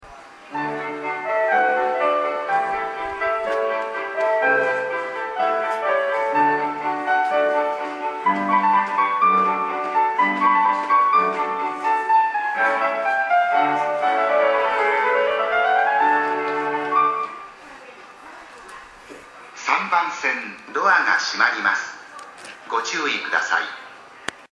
３番線常磐線
発車メロディー